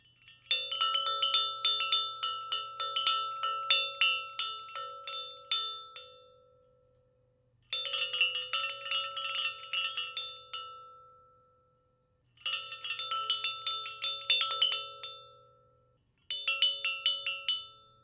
Cowbell
96k bell bong cowbell ding livestock metal pling sound effect free sound royalty free Sound Effects